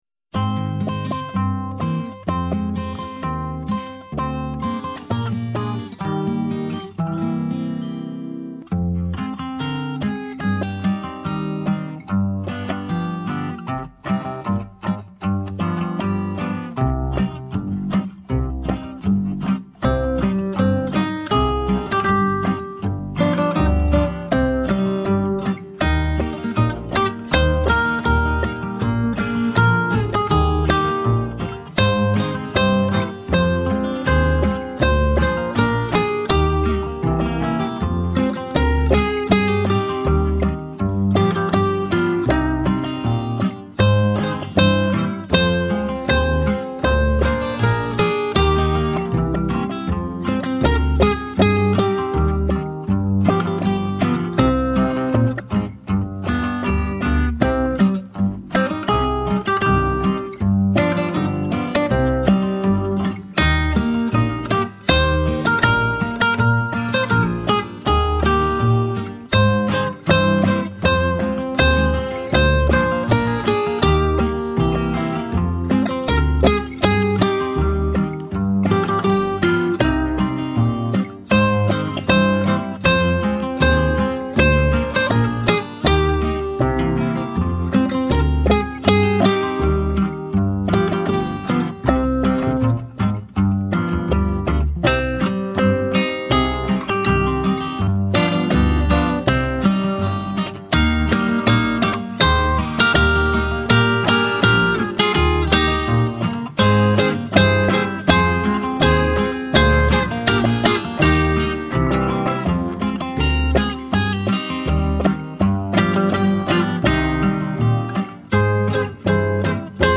Прослушать минусовку